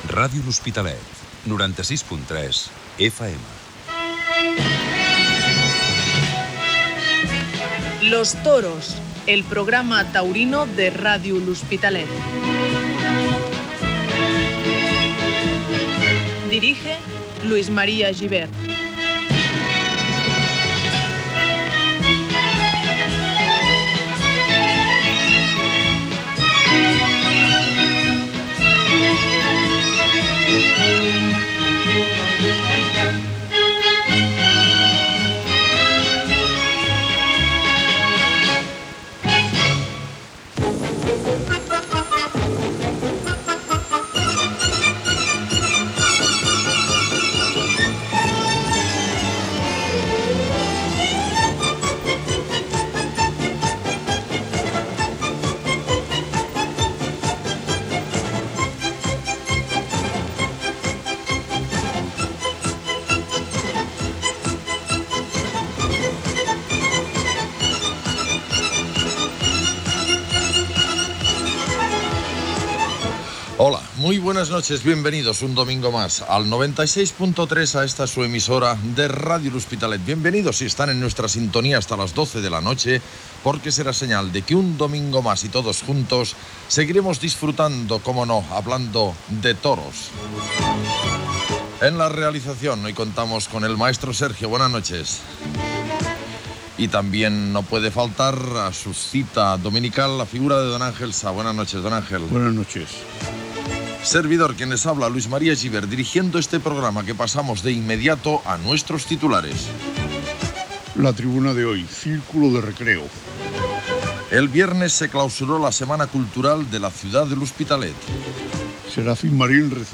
notícies i tribuna d'opinió. Gènere radiofònic Informatiu